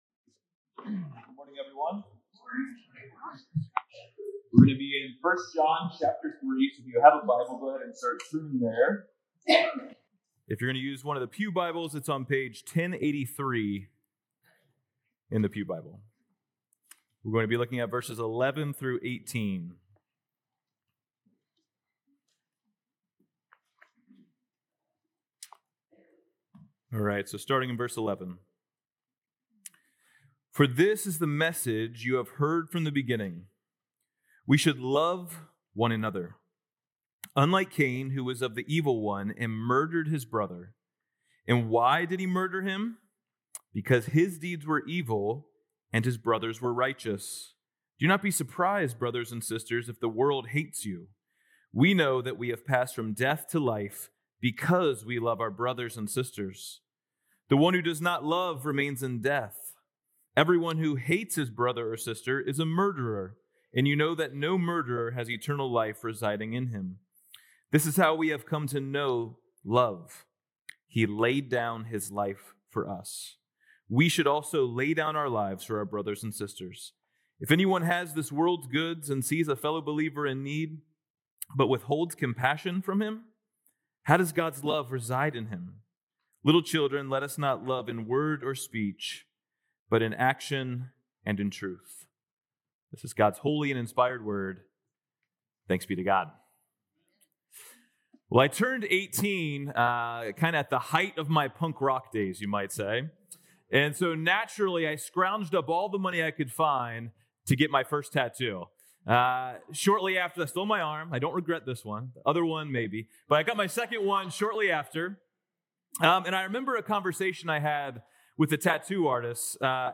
Jan 4th Sermon